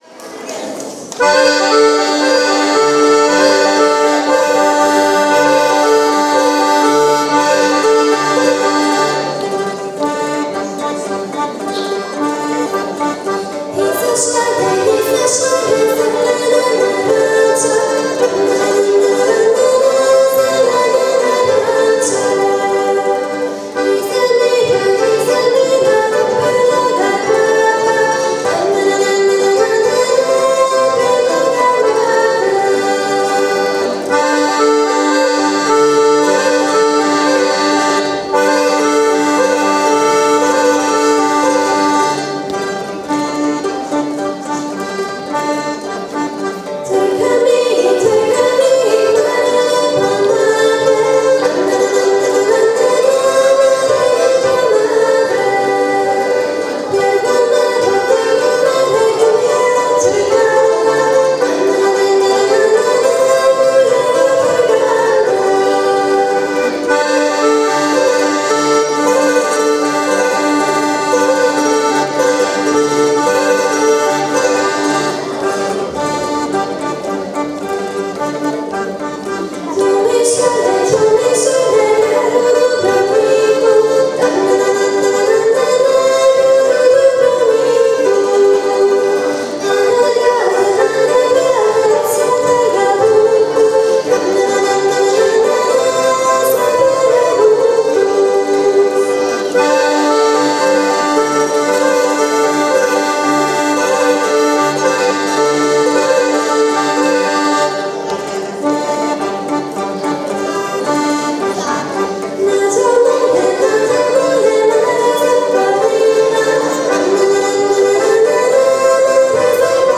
II. SMOTRA MALIH KLAPA
program su uveličali mandolinisti "Koparići"